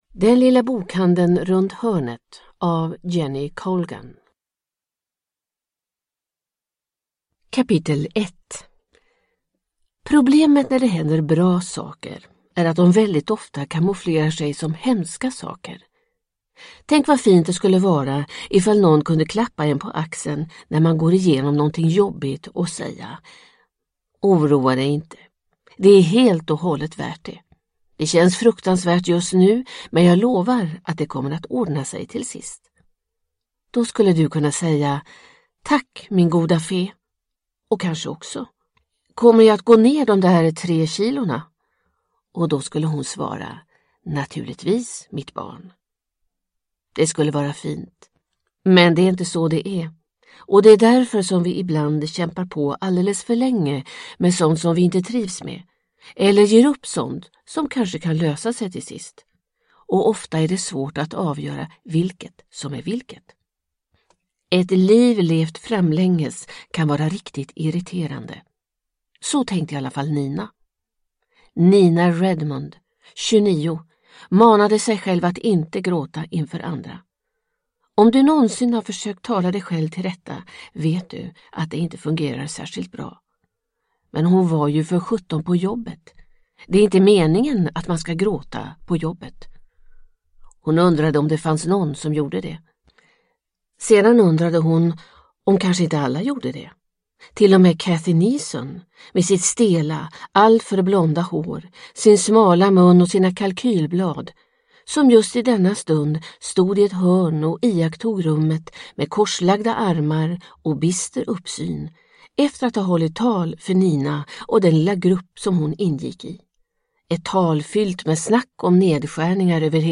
Den lilla bokhandeln runt hörnet – Ljudbok – Laddas ner